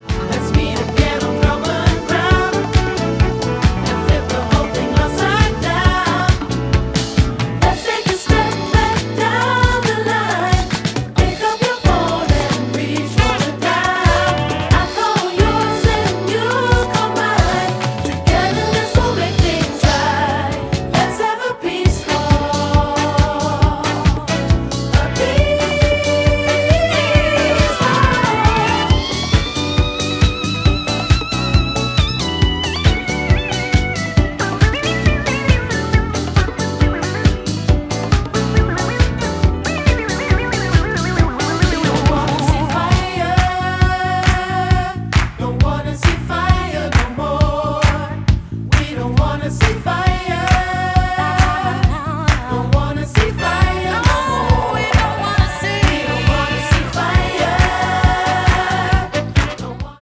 and bolstered throughout by a dedicated brass section.